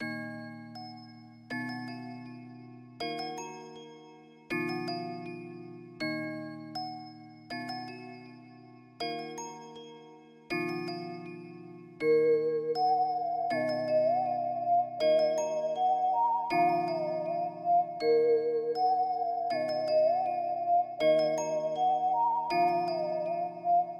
Tag: 160 bpm Trap Loops Piano Loops 4.04 MB wav Key : D